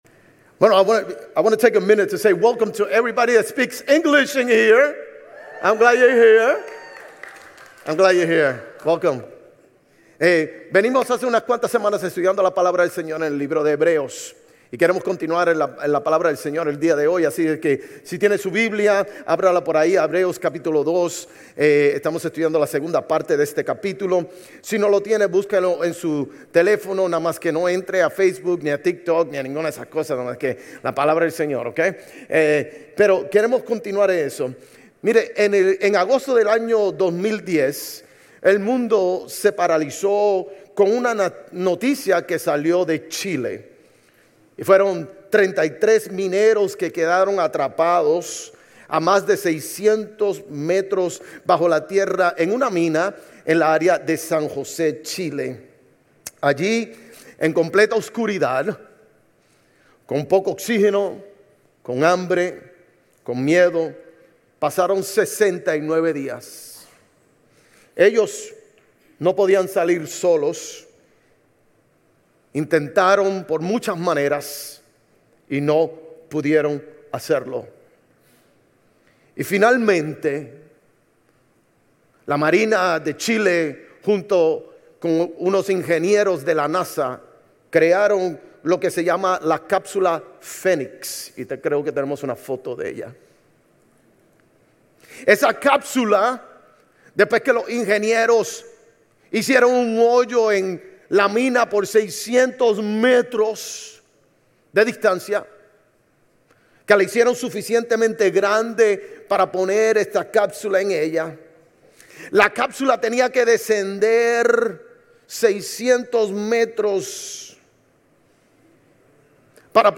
Sermones Grace Español 9_28 Grace Espanol Campus Sep 29 2025 | 00:29:12 1x 00:00 / 00:29:12 Subscribe Share RSS Feed Share Link Embed